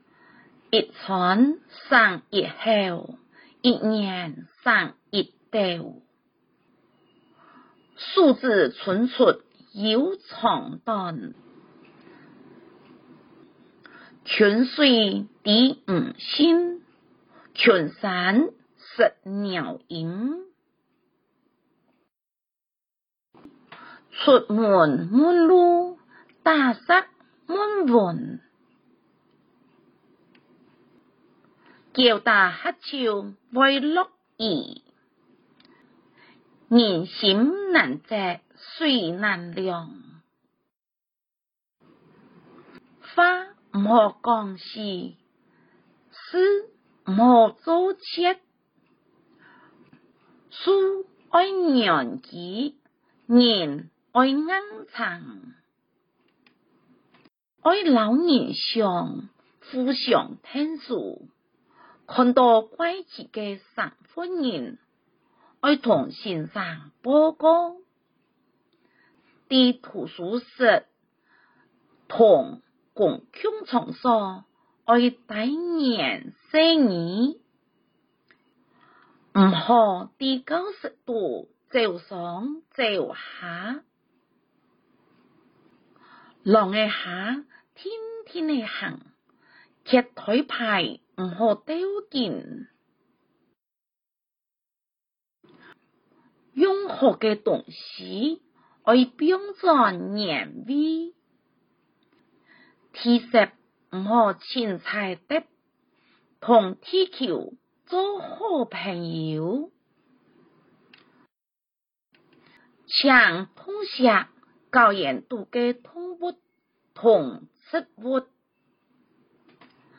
四縣腔聲音檔
113上客語環境貼條_四縣腔.mp3